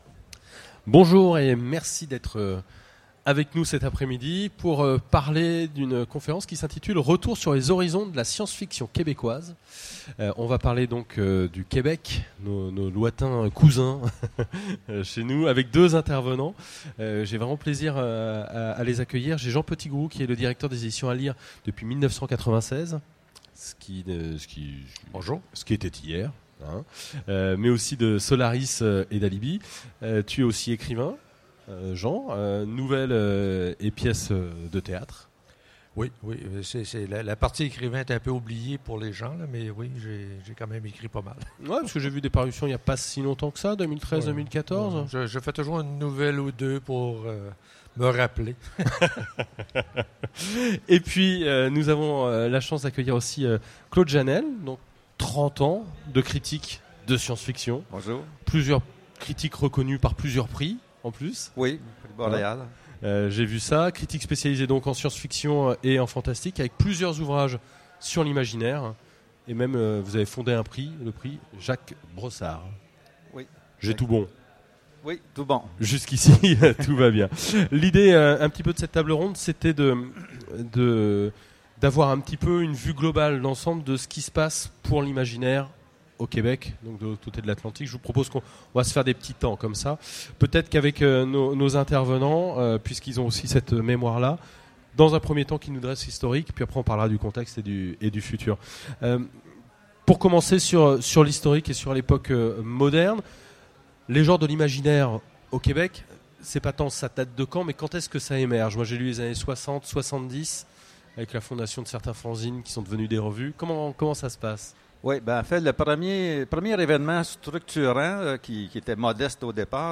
Utopiales 2015 : Conférence Retour sur les horizons de la science-fiction québécoise
Conférence